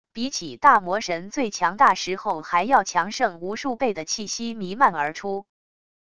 比起大魔神最强大时候还要强盛无数倍的气息弥漫而出wav音频生成系统WAV Audio Player